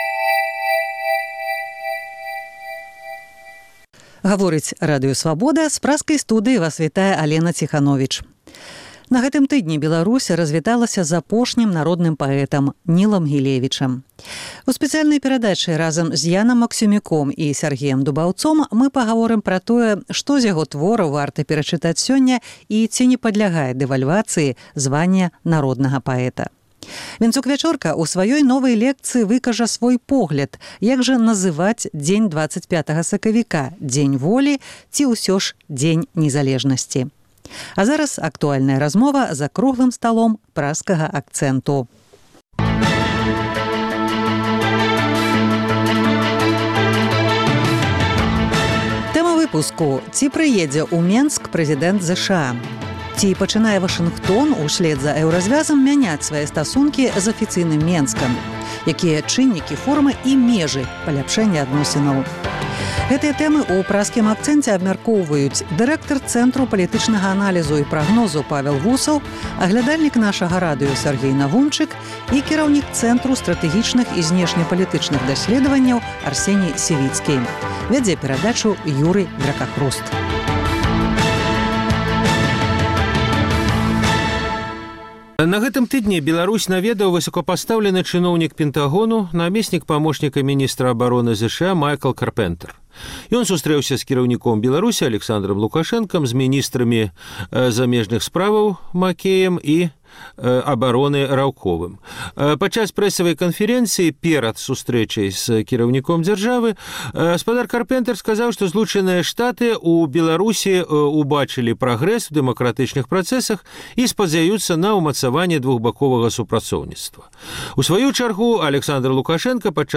Якой будзе палітыка ЗША адносна Беларусі пры новым прэзыдэнце? Гэтыя пытаньні ў Праскім акцэнце абмяркоўваюць